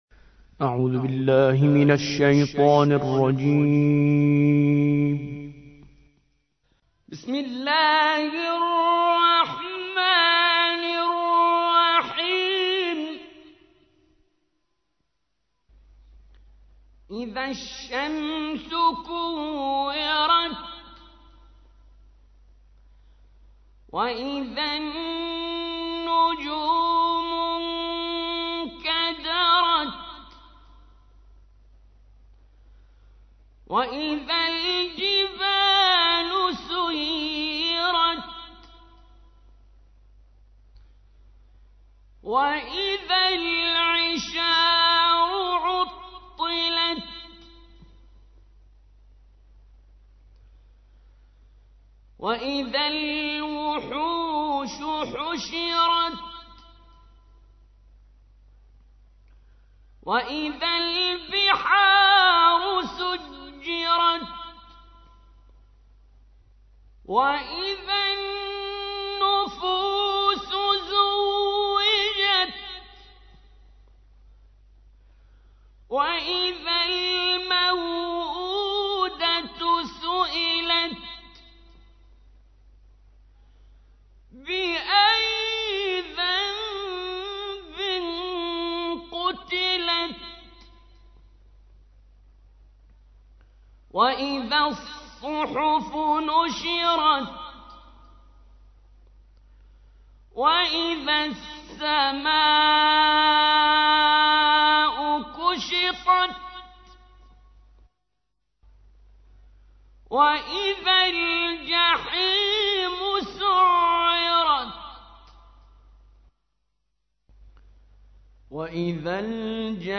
تحميل : 81. سورة التكوير / القارئ كريم منصوري / القرآن الكريم / موقع يا حسين